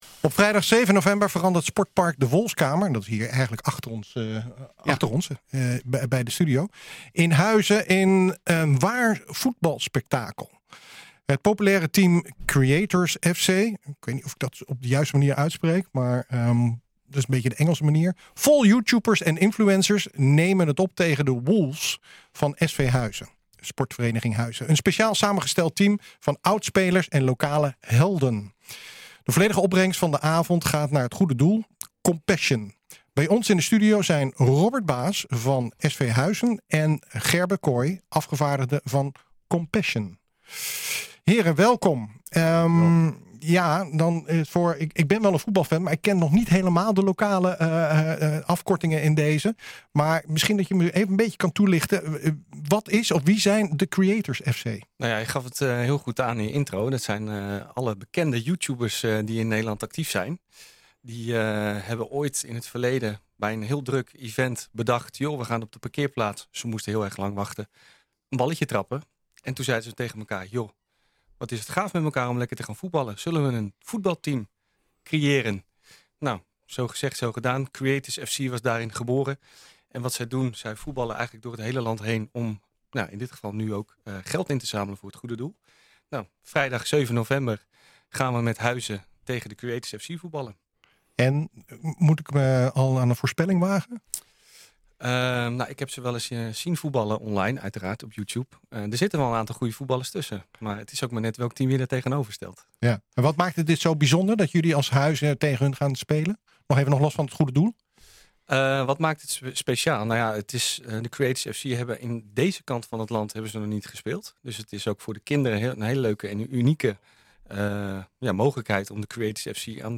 Het populaire team Creators FC, vol YouTubers en influencers, neemt het op tegen The Wolves van s.v. Huizen, een speciaal samengesteld team van oud-spelers en lokale helden. De volledige opbrengst van de avond gaat naar het goede doel Compassion. Bij ons in de studio